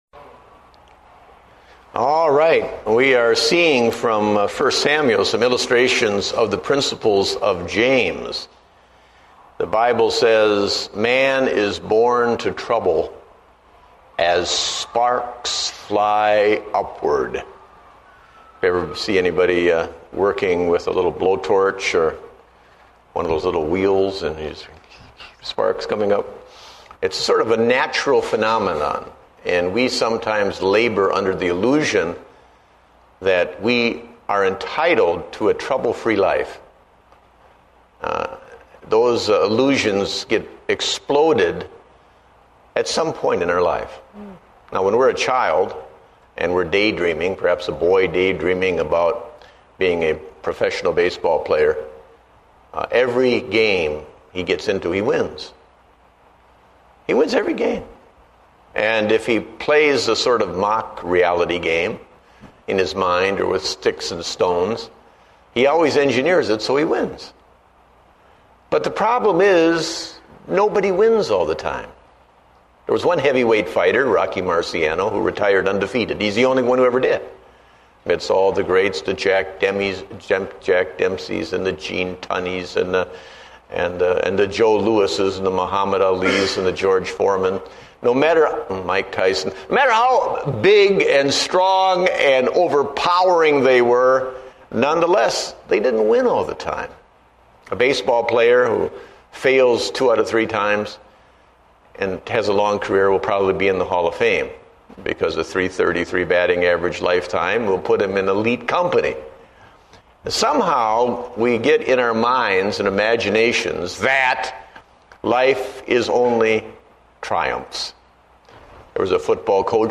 Date: August 3, 2008 (Adult Sunday School)